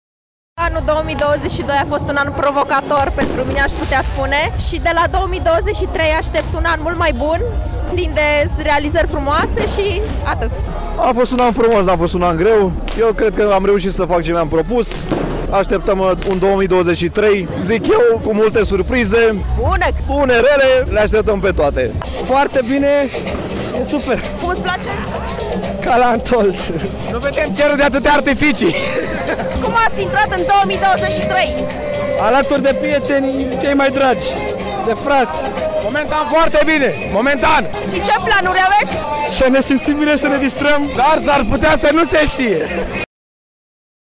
Mii de brașoveni și turiști au ales să petreacă Revelionul în Piața Sfatului
VOXURI-NEW-YEAR-BRASOV.mp3